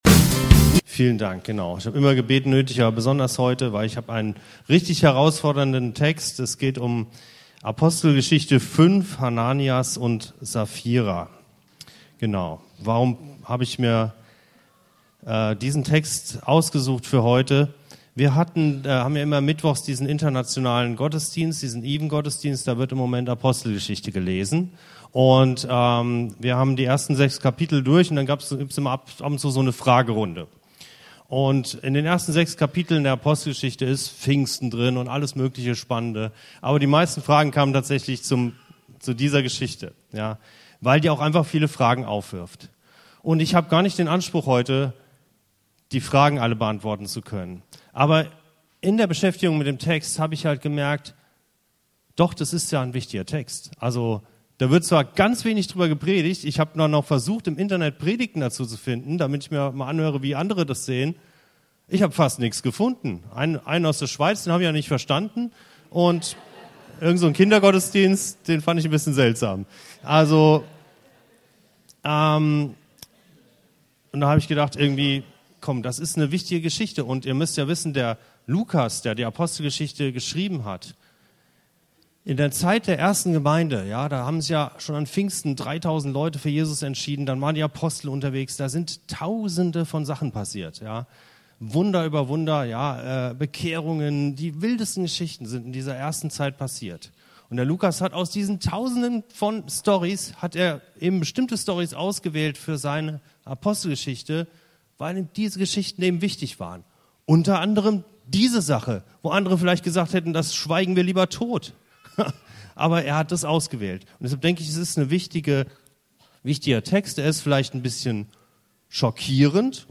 Diese Predigt geht den Fragen nach: Was steckt hinter dieser Warnung?